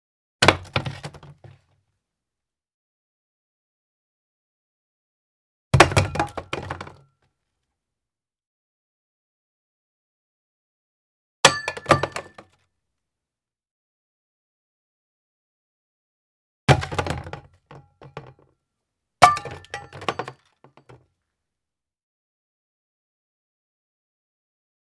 household
Garbage Can Plastic Toss Plastic Bottle into Empty Can